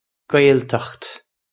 Gwayl-tukht
This is an approximate phonetic pronunciation of the phrase.